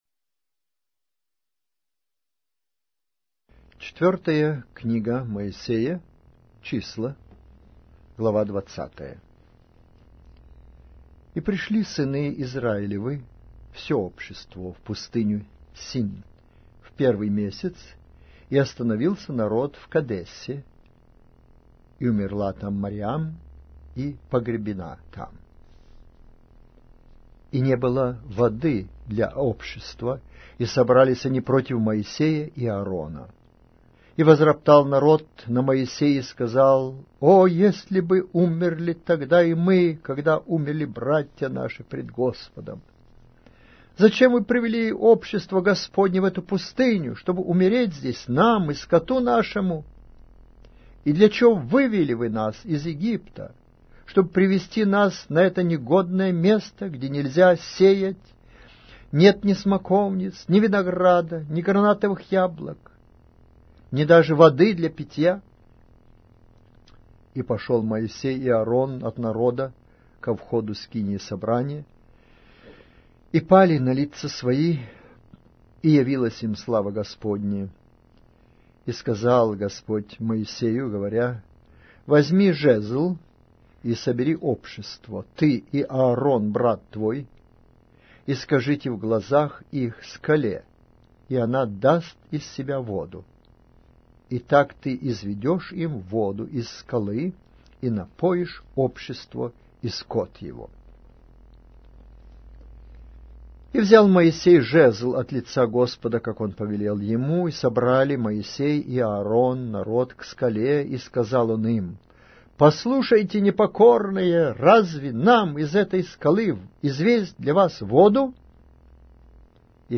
Аудиокнига: Книга 4-я Моисея.